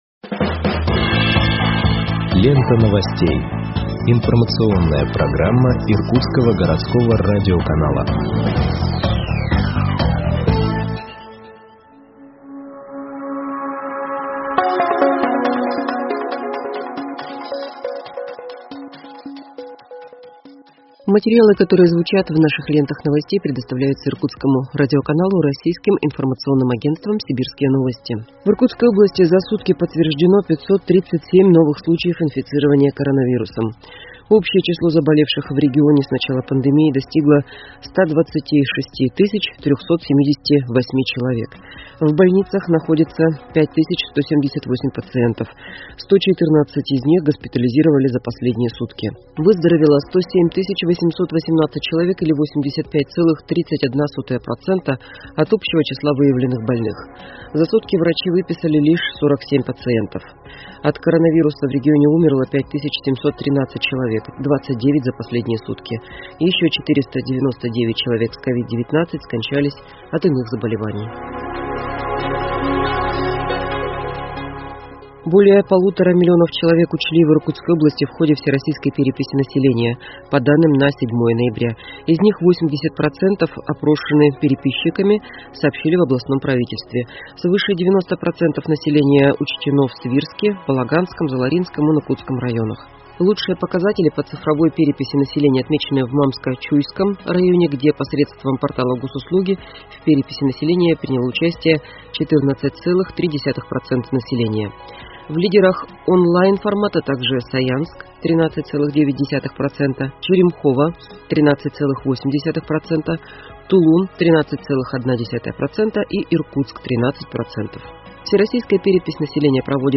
Выпуск новостей в подкастах газеты Иркутск от 09.11.2021